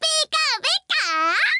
File:Pikachu voice sample SSBB.oga
Pikachu_voice_sample_SSBB.oga.mp3